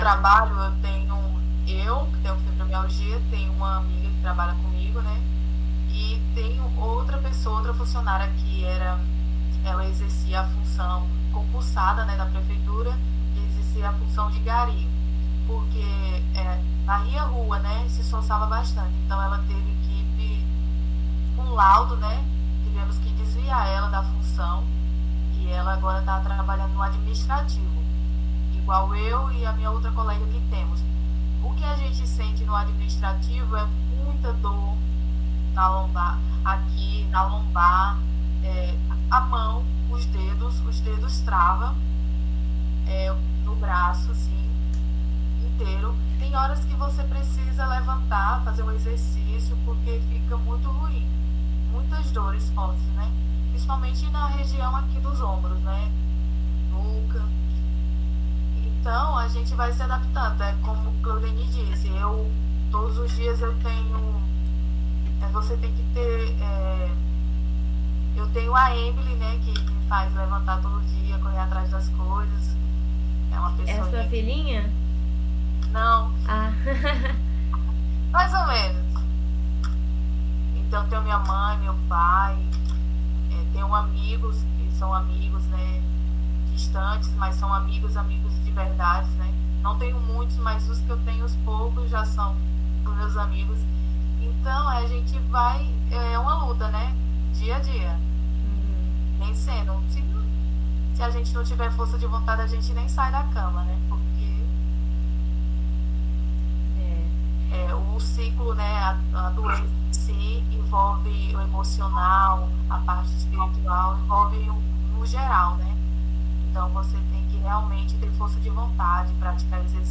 Depoimento completo